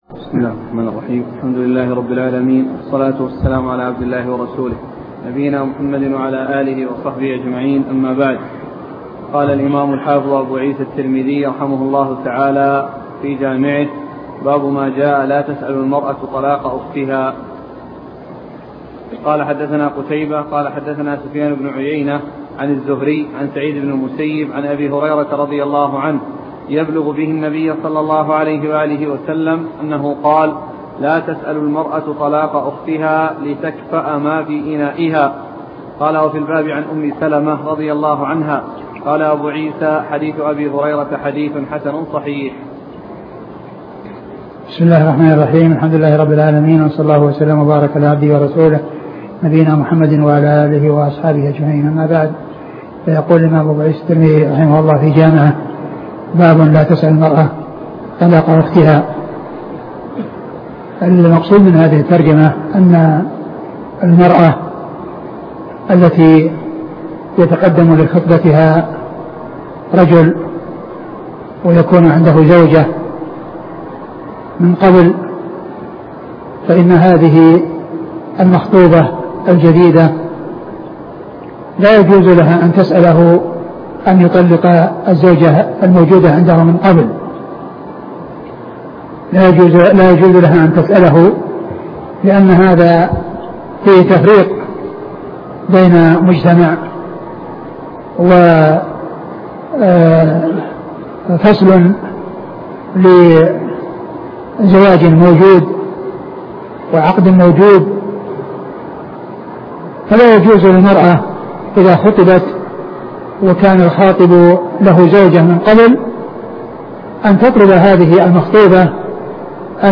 الدروس والسلاسل